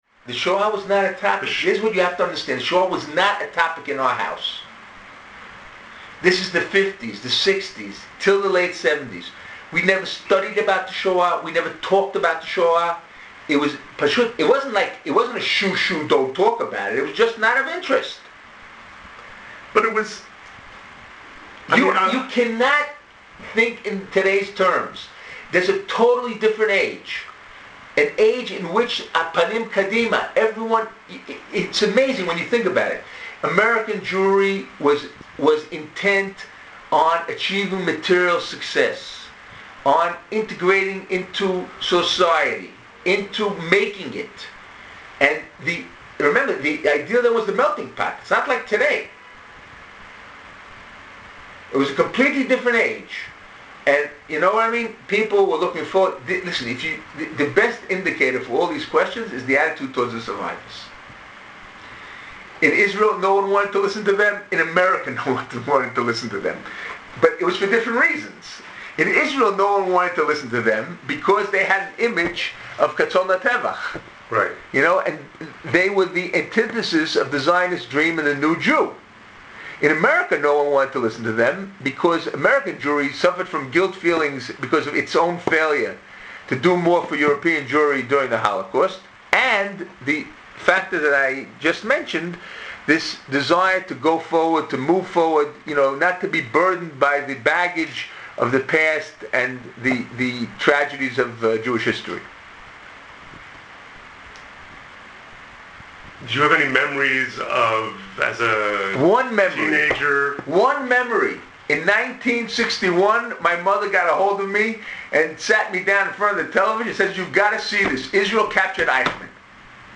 Brief audio excerpts from Zuroff’s interview for Jewish Sages of Today